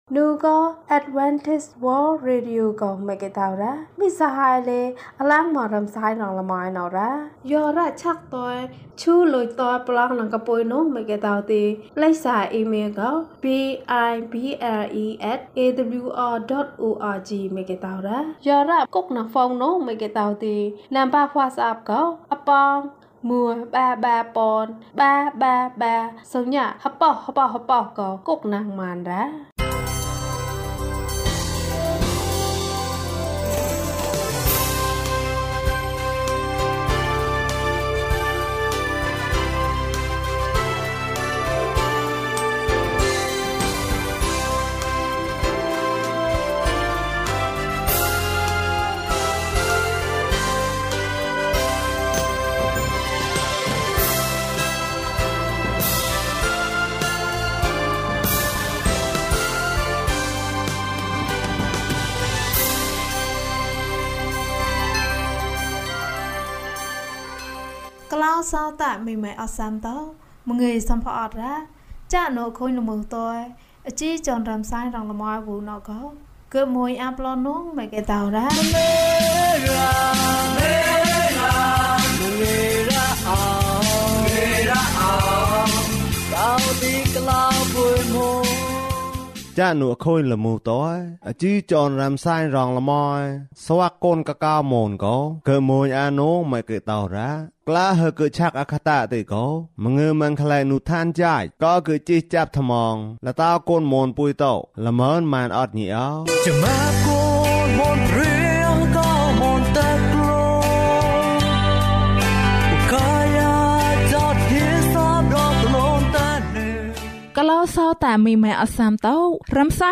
ခရစ်တော်ထံသို့ ခြေလှမ်း။၄၃ ကျန်းမာခြင်းအကြောင်းအရာ။ ပုံပြင်။ ဓမ္မသီချင်း။ တရားဒေသနာ။